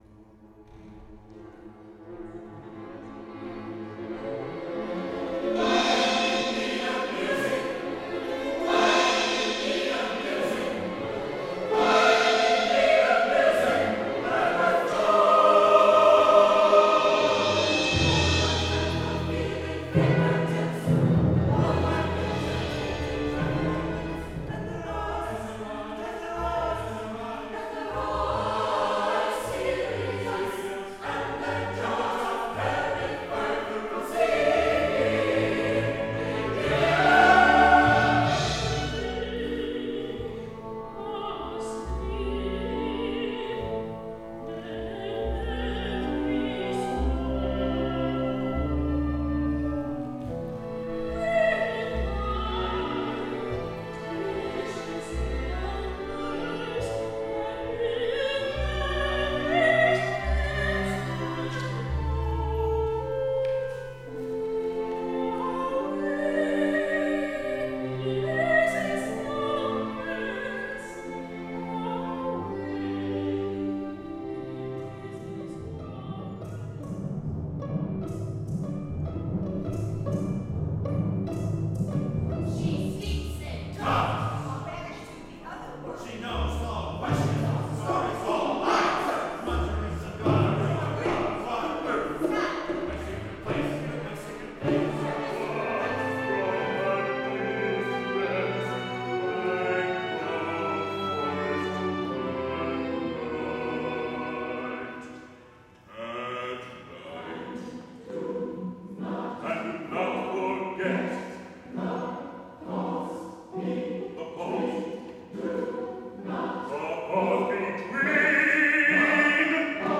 Mixed Chorus - With Accompaniment
mezzo, baritone, chorus, children's chorus & small orchestra